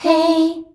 HEY     E.wav